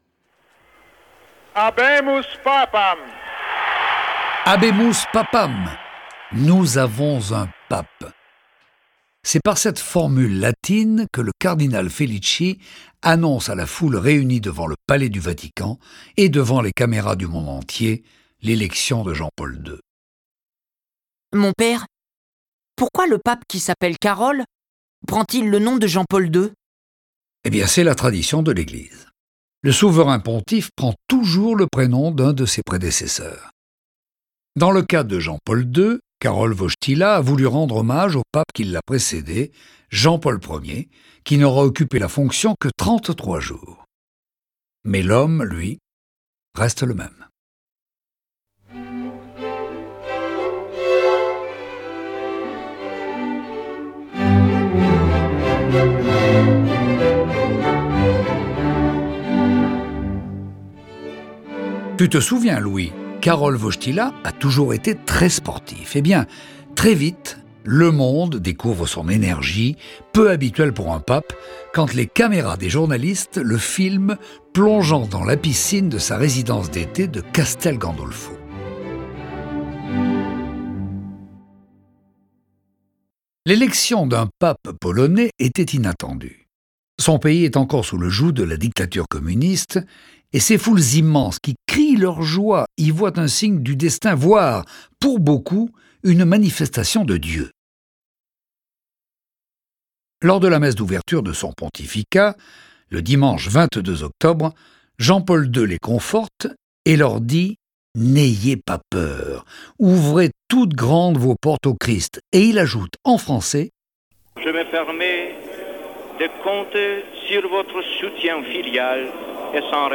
Diffusion distribution ebook et livre audio - Catalogue livres numériques
Cette version sonore de la vie de Jean-Paul II est animée par six voix et accompagnée de plus de trente morceaux de musique classique.